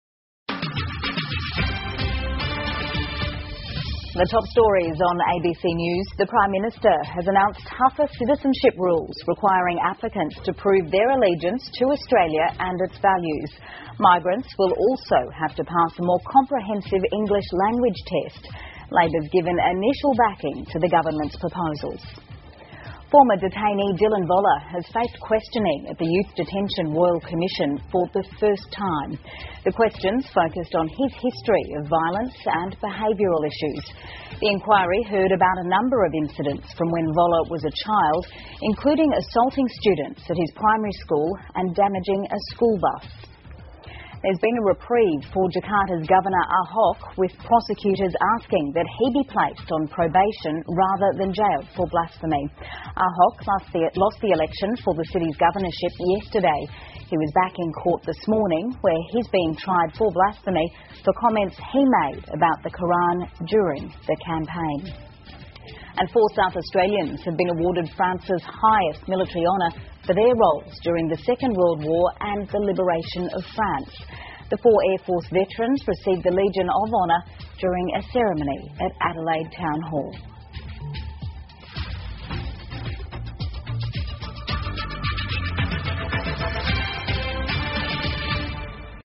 澳洲新闻 (ABC新闻快递) 澳大利亚政府宣布提高外国人入籍门槛 印尼检方要求轻判华裔省长 听力文件下载—在线英语听力室